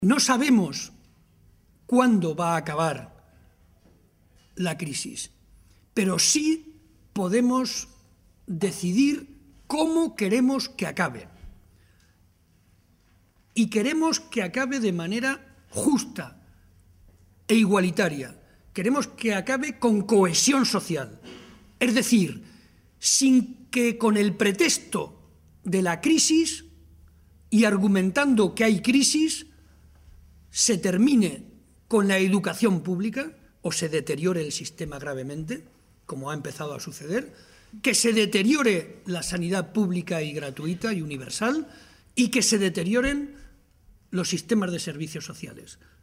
Momento de la rueda de prensa.